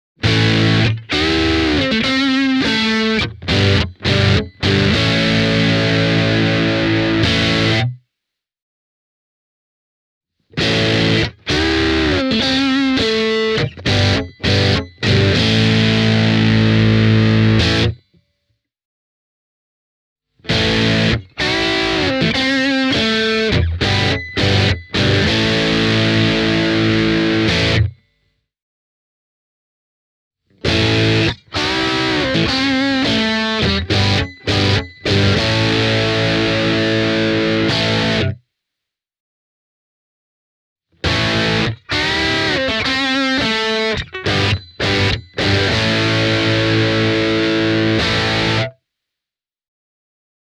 Sterling S.U.B. Silo3 -kitara on niin monipuolinen, että sillä pystyy kattamaan käytännössä koko särösoundien kirjon – miedosta perinne-Bluesista nykypäivän Metalliin.